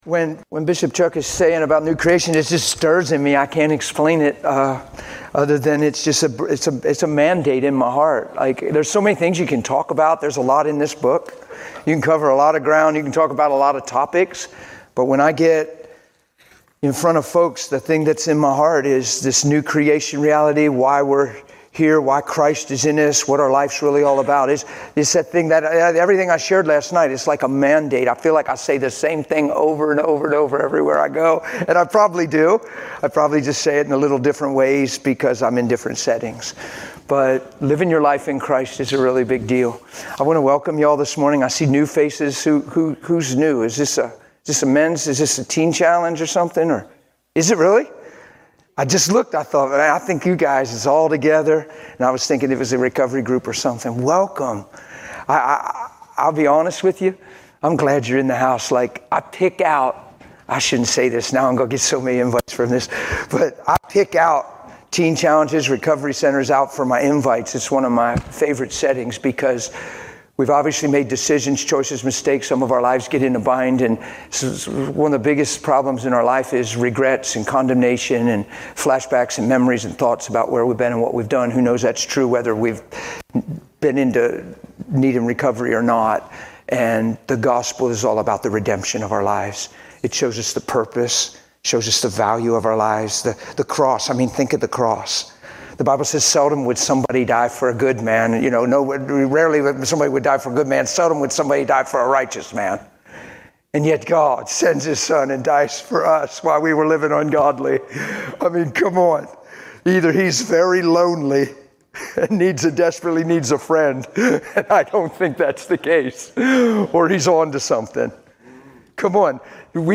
Service Type: Conference